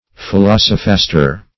Search Result for " philosophaster" : The Collaborative International Dictionary of English v.0.48: Philosophaster \Phi*los"o*phas`ter\, n. [L., a bad philosopher, fr. philosophus: cf. OF. philosophastre.] A pretender to philosophy.
philosophaster.mp3